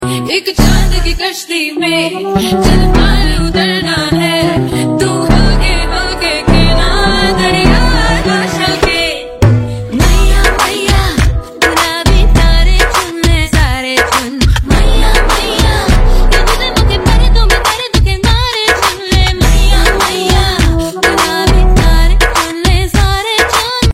Their harmonious voices
soothing waves